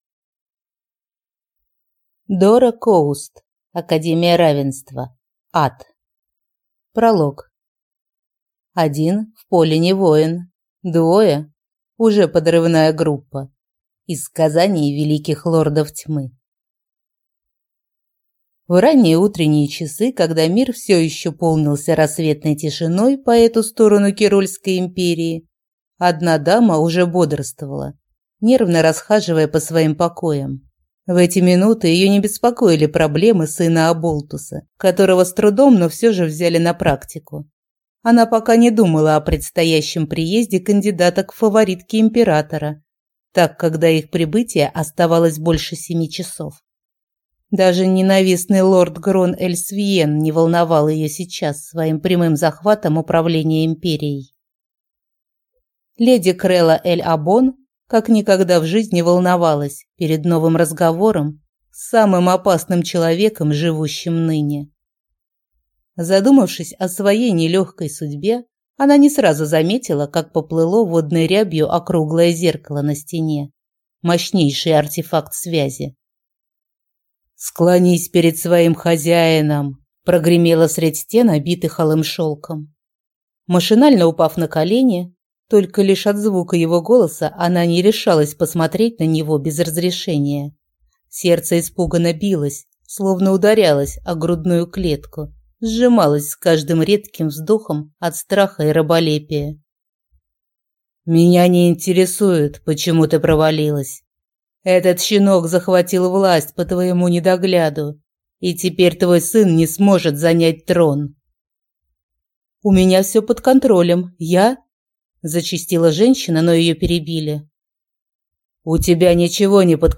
Аудиокнига Академия Равенства. Ад | Библиотека аудиокниг